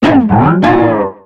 Cri de Miasmax dans Pokémon X et Y.